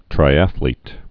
(trī-ăthlēt)